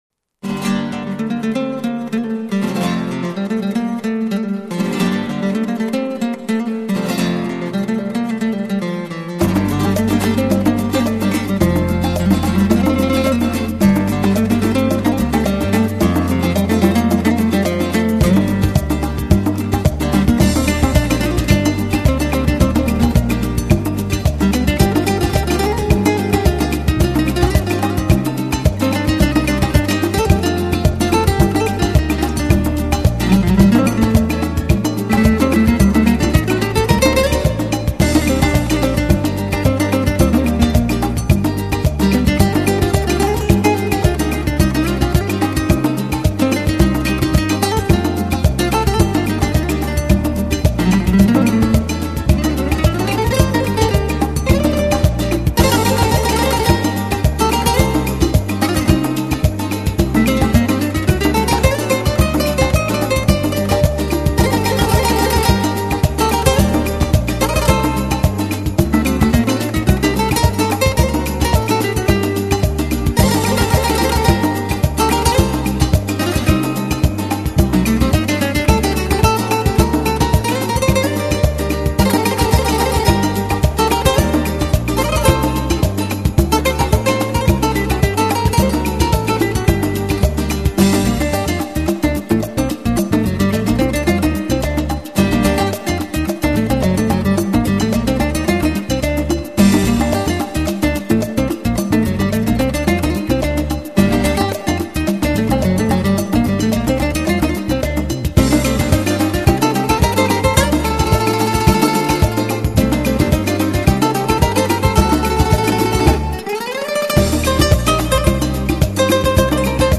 新弗拉门戈吉他大师